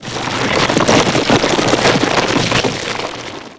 Rocks/ stones falling